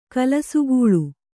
♪ kalasugūḷu